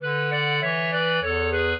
clarinet
minuet6-8.wav